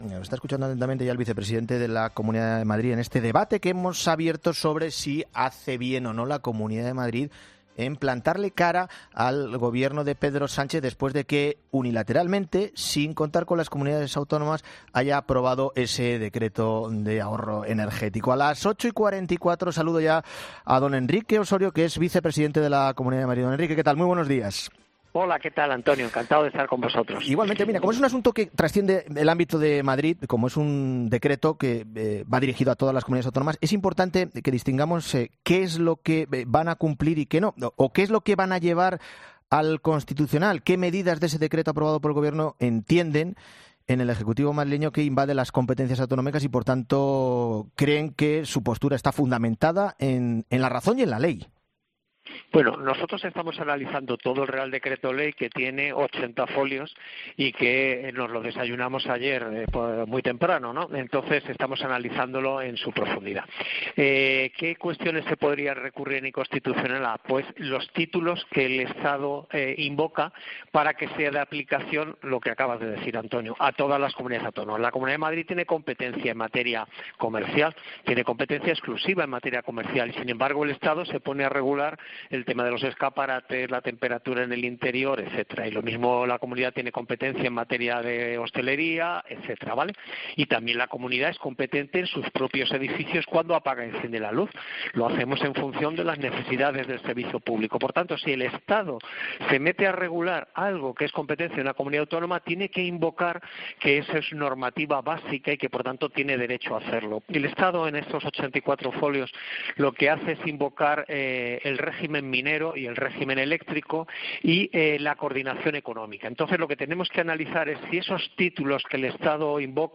El vicepresidente de la Comunidad de Madrid, Enrique Ossorio , ha pasado por los micrófonos de COPE para comentar y analizar la decisión del Gobierno madrileño en no aceptar la propuesta eléctrica del Ejecutivo.